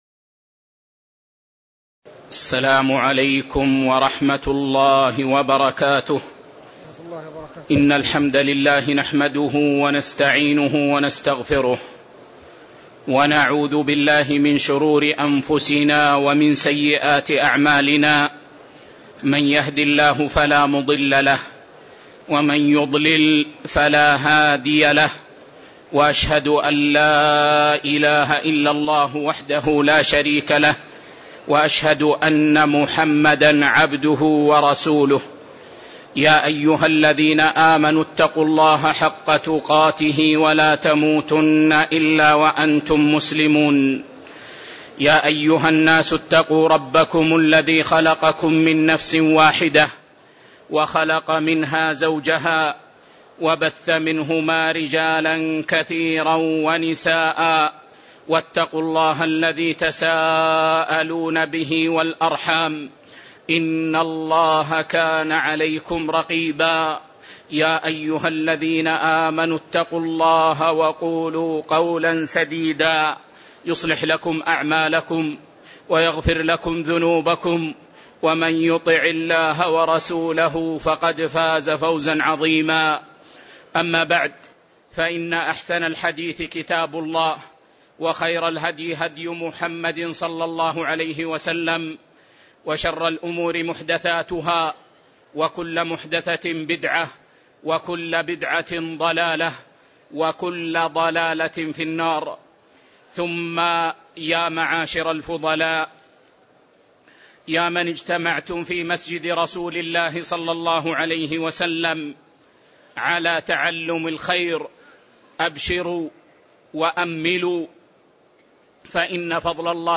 تاريخ النشر ٢٥ جمادى الأولى ١٤٣٧ هـ المكان: المسجد النبوي الشيخ